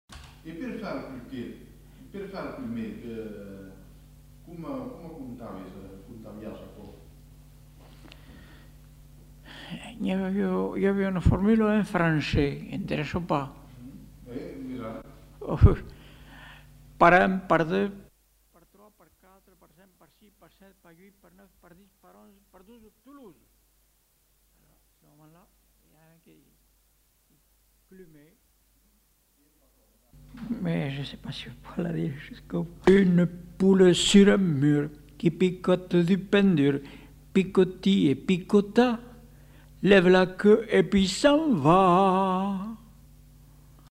Aire culturelle : Périgord
Genre : forme brève
Effectif : 1
Type de voix : voix d'homme
Production du son : récité
Classification : comptine